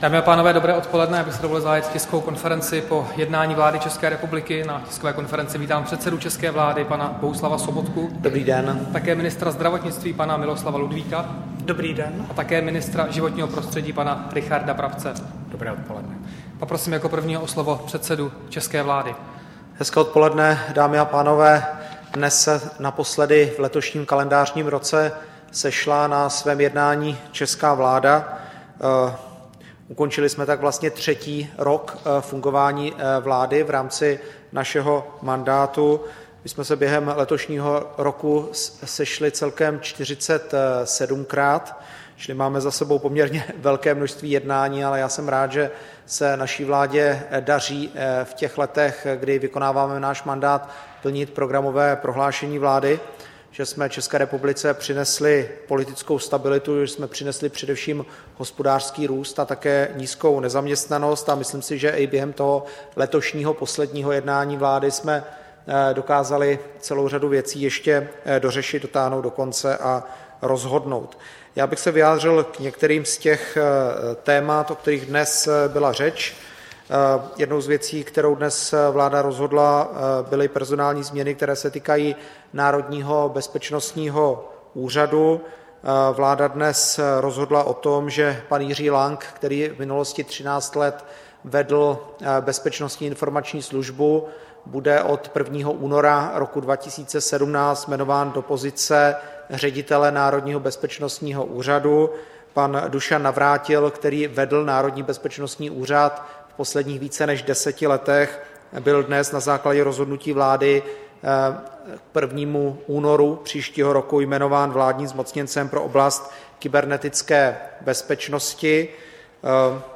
Tisková konference po jednání vlády, 19. prosince 2016